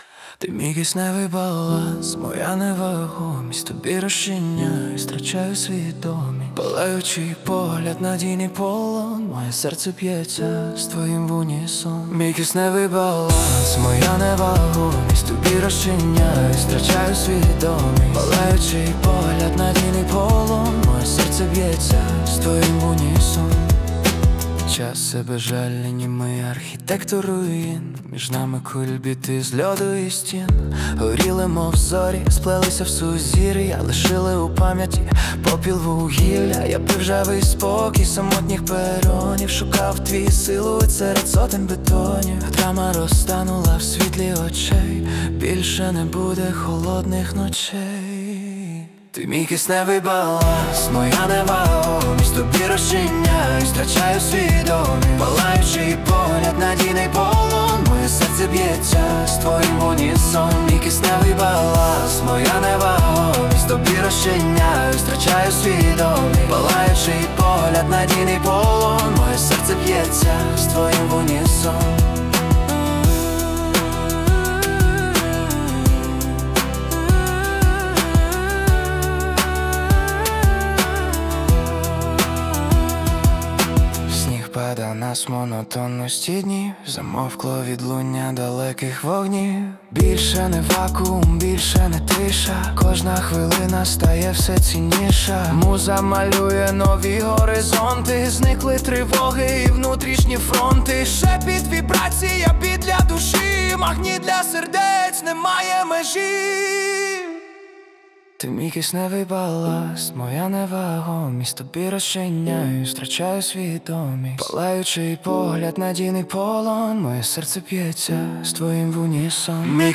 Красива ніжна стильна композиція з сенсом